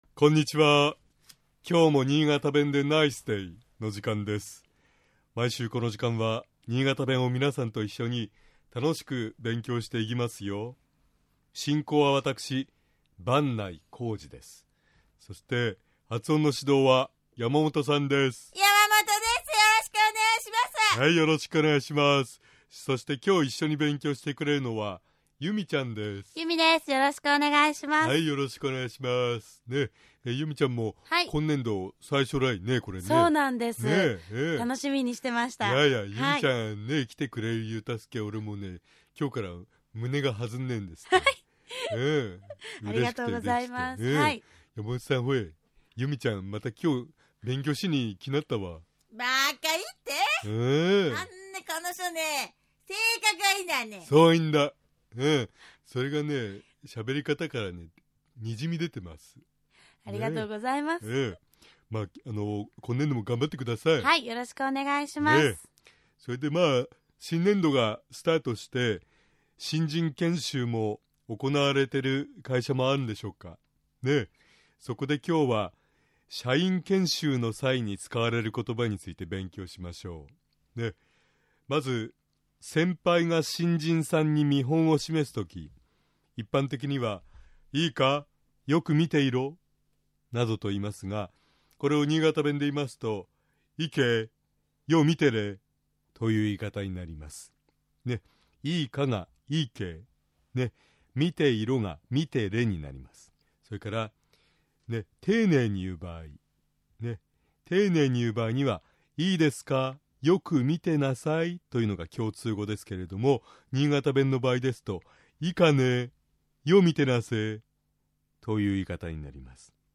尚、このコーナーで紹介している言葉は、 主に新潟市とその周辺で使われている方言ですが、 それでも、世代や地域によって、 使い方、解釈、発音、アクセントなどに 微妙な違いがある事を御了承下さい。 110411niigataben.mp3 ポッドキャストは携帯ではサービスしていません。